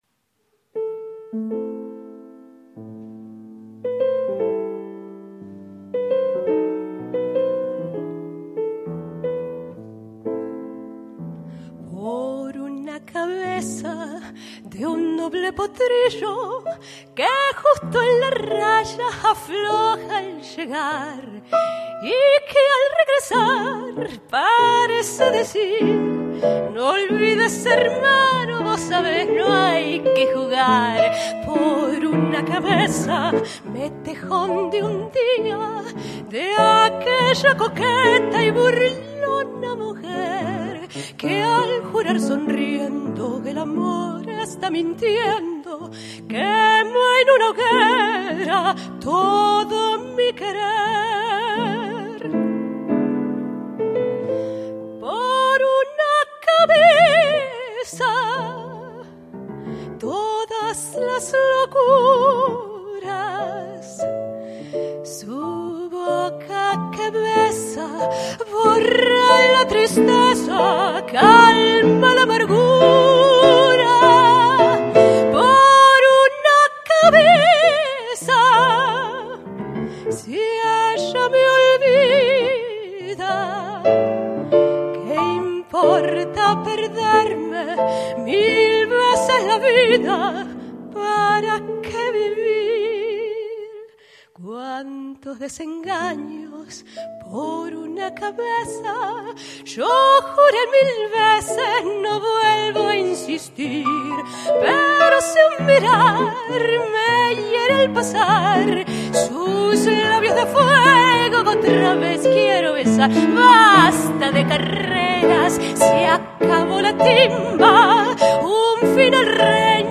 pianoforte.
Settimana di cultura argentina, Pesaro (live recording